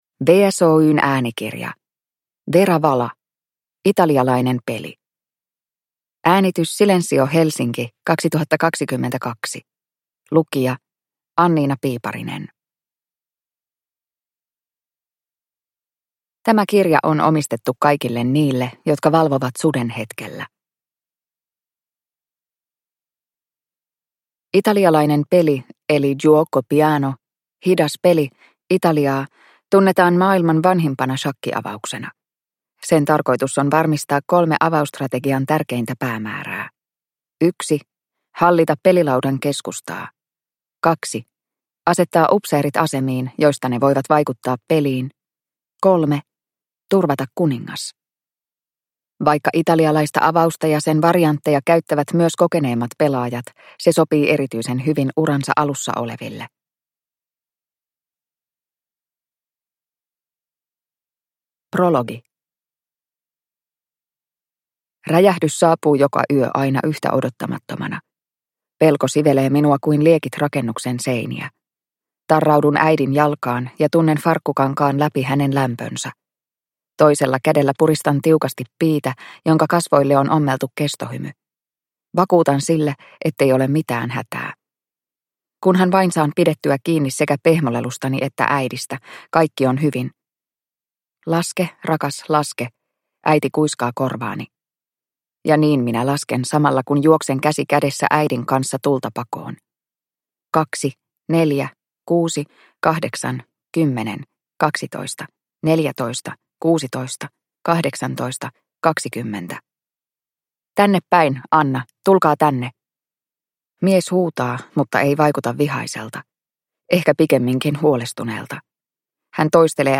Italialainen peli (ljudbok) av Vera Vala | Bokon